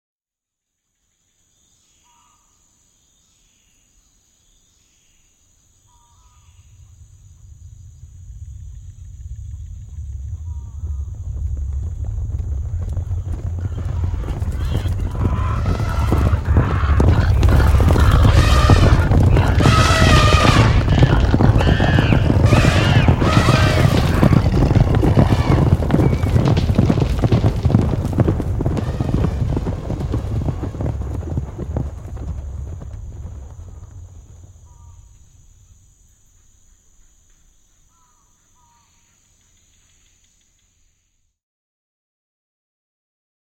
Громкие, ритмичные удары копыт создают эффект присутствия – используйте для звукового оформления, релаксации или творческих проектов.
Шум панического бегства зверей из леса